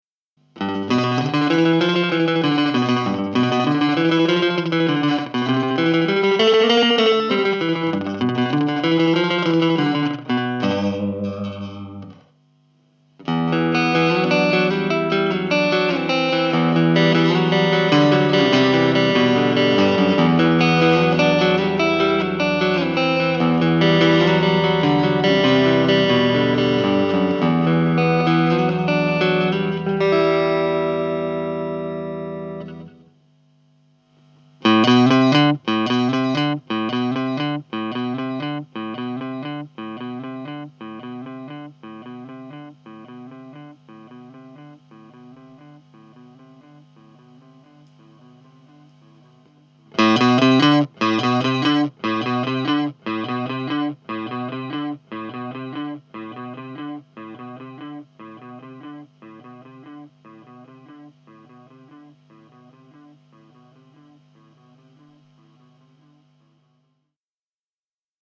Mooer RepeaterЦифровой дилей.
Хороший простой дилей.